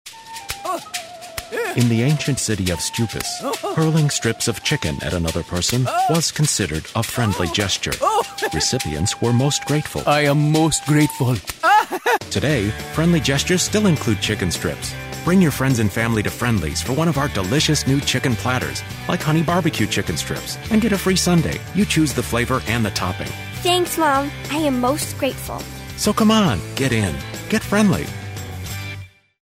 Commercial #1